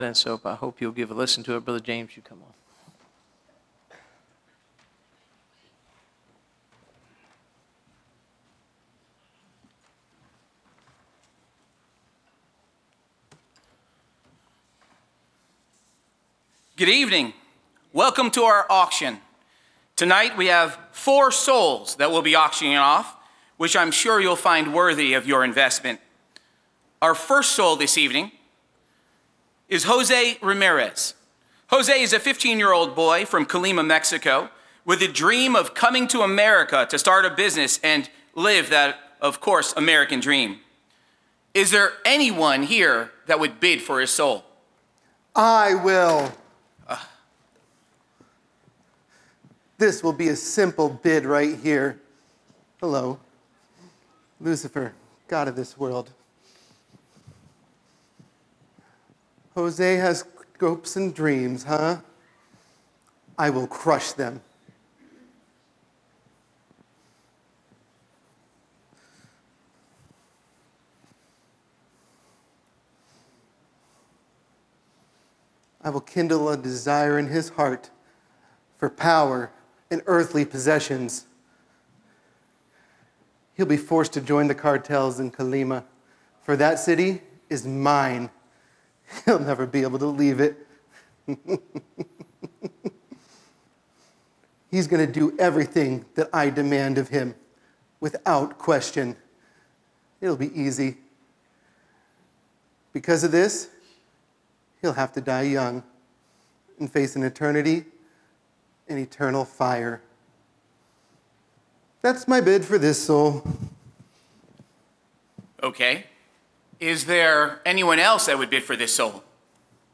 Series: (Missions Conference 2025)
Preacher